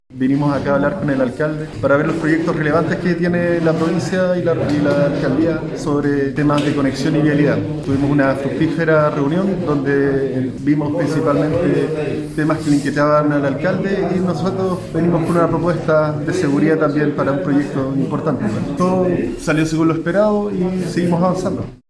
01-DANIEL-DIAZ-Director-Regional-Vialidad.mp3